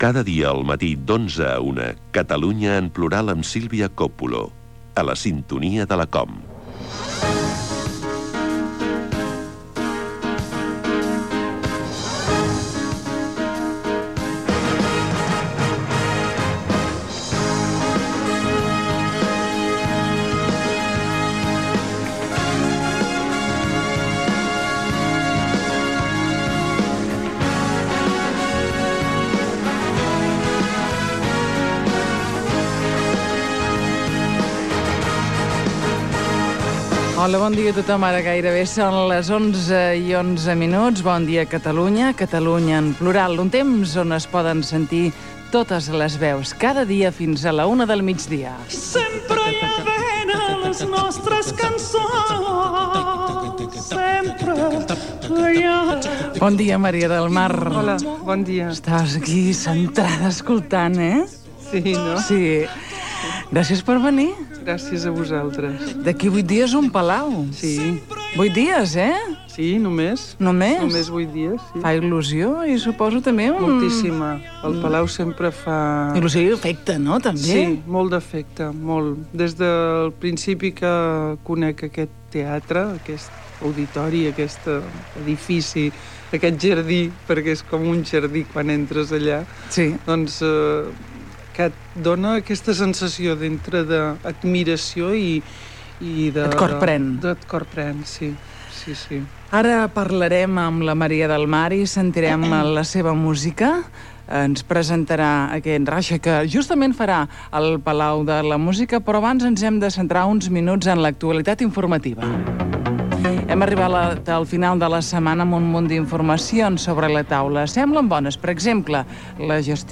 Indicatiu del programa, presentació de la cantant Maria del Mar Bonet, qui pocs dies després actuaria al Palau de la Música Catalana per presentar el seu disc "Raixa". Repàs a l'actualitat del dia.
Info-entreteniment